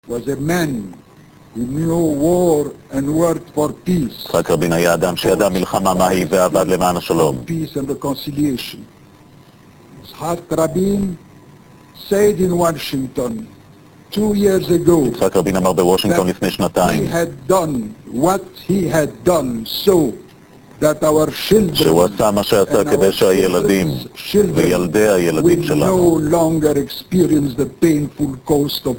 Click on the any of the icons below to download and hear speeches made during the Jerusalem Rabin memorial ceremony of November 6th 1995.
Boutros Ghali speech snippet 33k